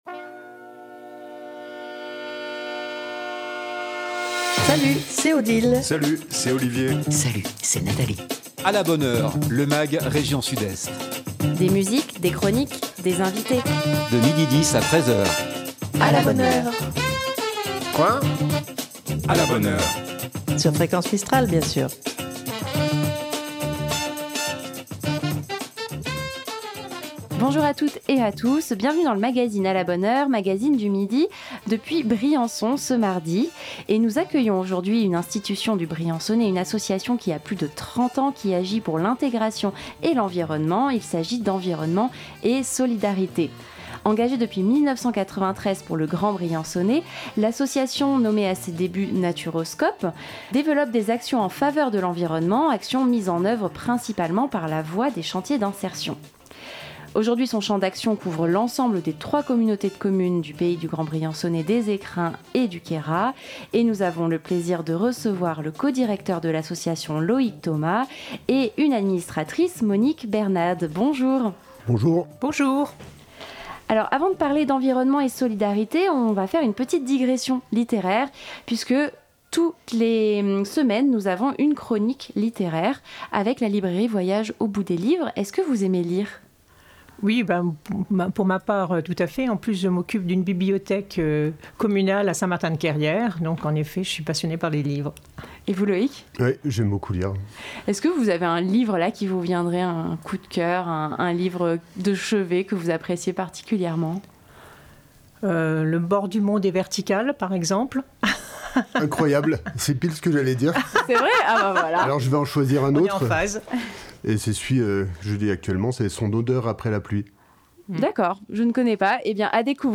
De Marseille à Briançon en passant par Manosque, sans oublier Dignes les bains, et Gap, un magazine régional, un Mag rien que pour vous, des invité.e.s en direct, des chroniques musique, cinéma, humour, littéraire, sorties et sur divers thèmes qui font l’actualité. Ce mardi nous accueillons une institution du Briançonnais, une association qui a plus de 30 ans, qui agit pour l'intégration et la protection de la nature, il s'agit d'Environnement et Solidarité.